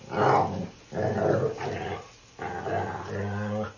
dog
bark_27010.wav